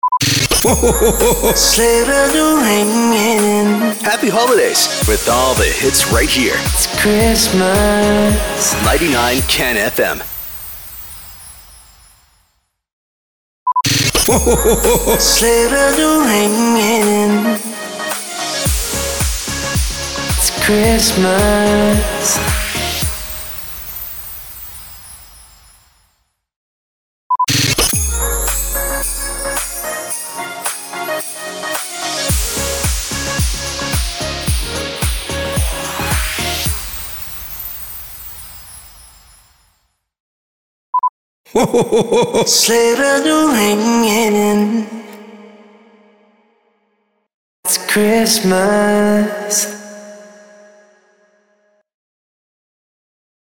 445 – SWEEPER – IT’S CHRISTMAS
445-SWEEPER-ITS-CHRISTMAS.mp3